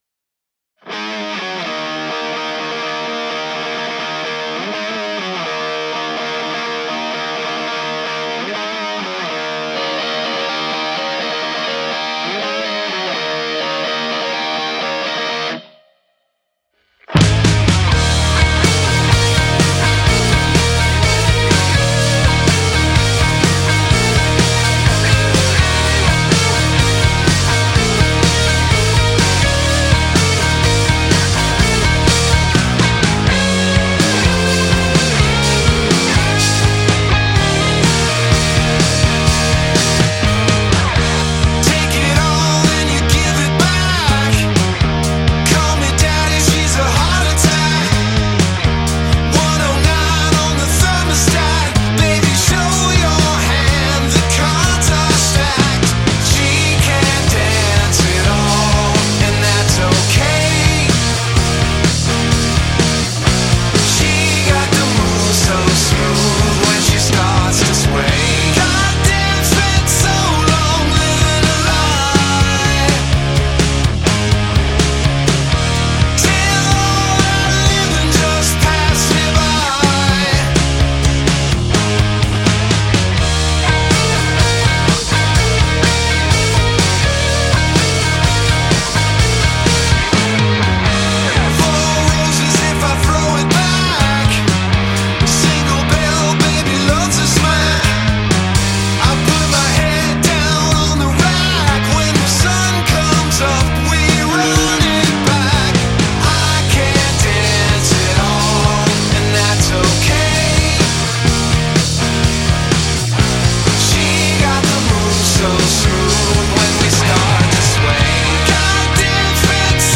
this is different that alot of my other stuff i.e heavier. it's a pretty dry mix maybe too much?